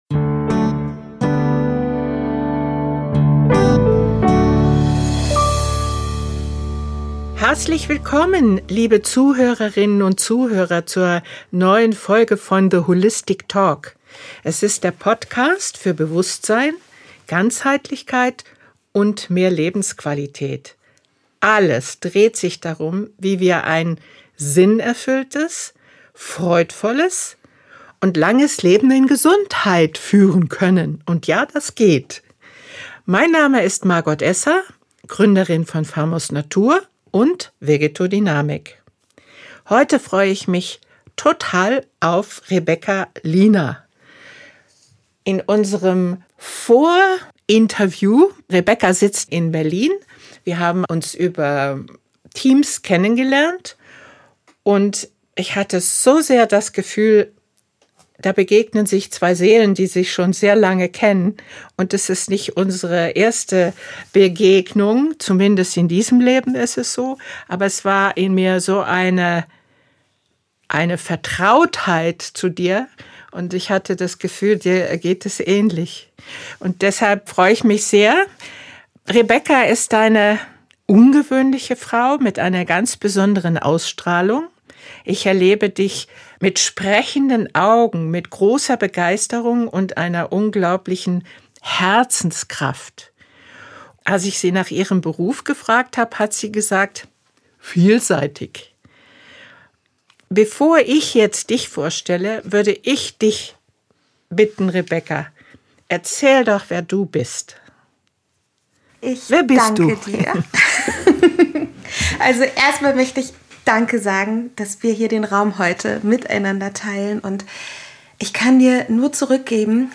Pflanzenkraft, Herzensverbindung & göttliches Bewusstsein Was nährt unsere Seele wirklich? In dieser besonderen Folge begegnen sich zwei Frauen, deren Seelen sich erkennen: